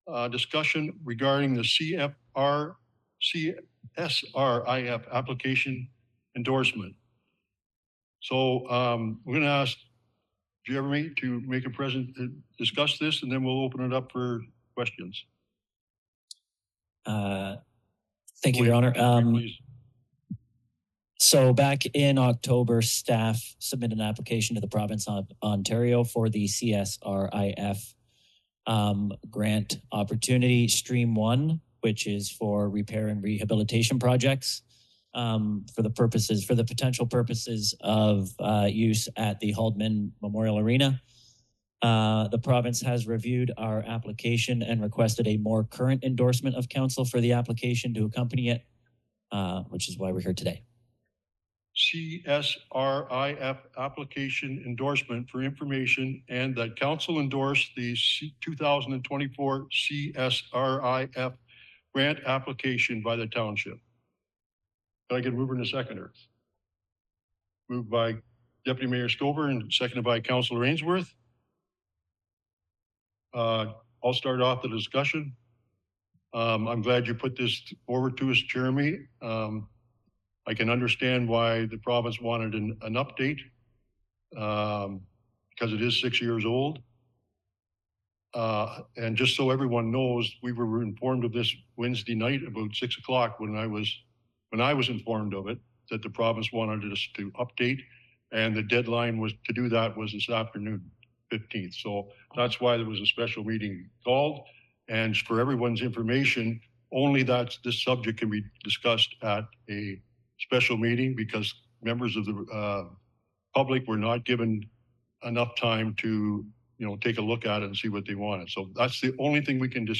Alnwick-Haldimand Township council took another step in its journey to keep an aging arena open for residents at a special council meeting on Nov. 15.